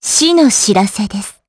Xerah-Vox_Skill2_jp.wav